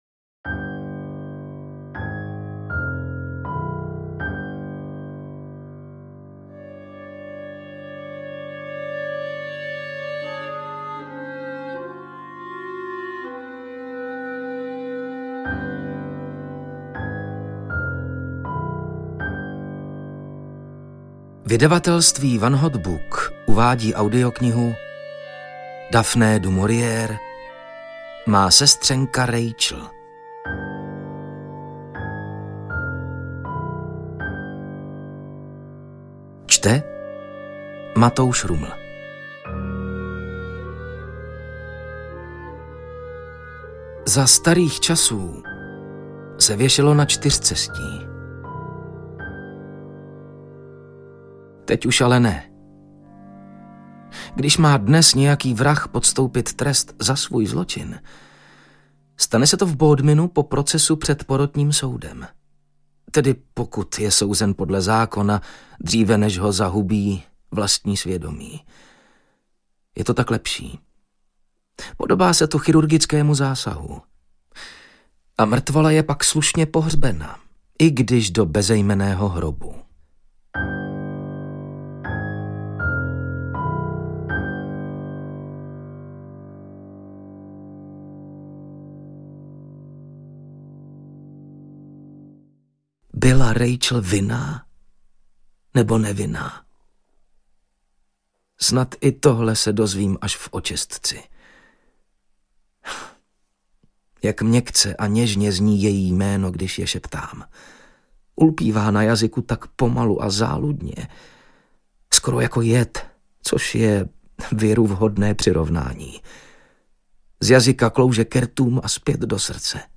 Má sestřenka Rachel audiokniha
Ukázka z knihy